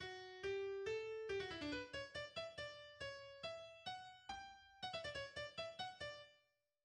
ト長調 、4分の2拍子、 変奏曲 形式。
スタッカート によって奏される、規則正しいリズムの伴奏部が時計の振り子を思わせる。